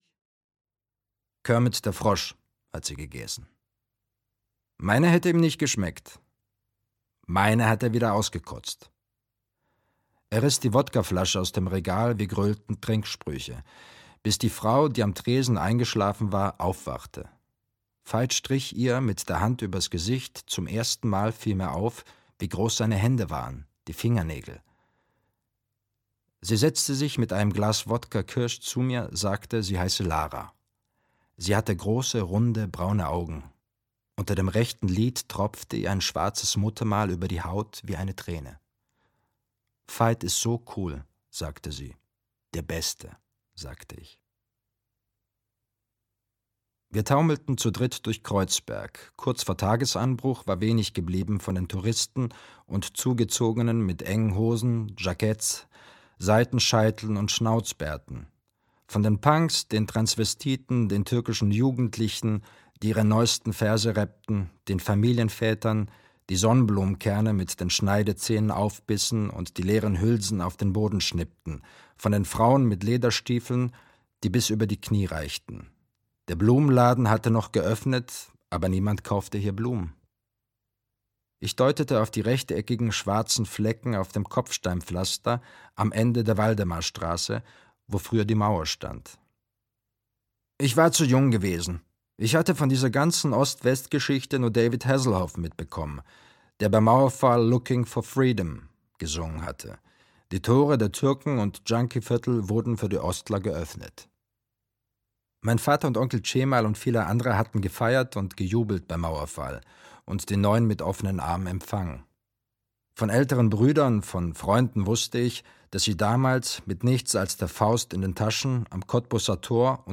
Die Ungehaltenen - Deniz Utlu - Hörbuch